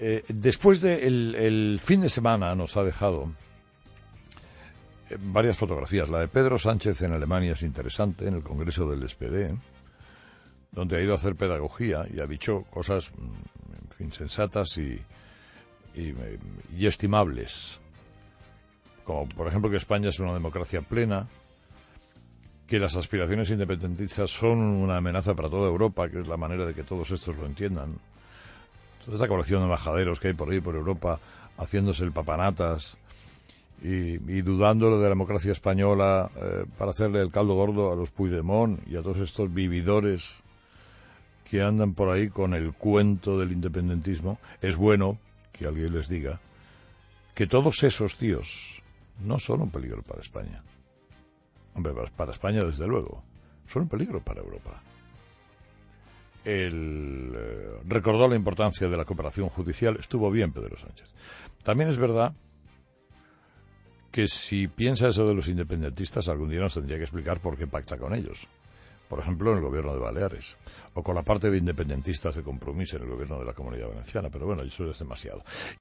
Este discurso de Pedro Sánchez ha formado parte del monólogo de Carlos Herrera este lunes en 'Herrera en COPE'.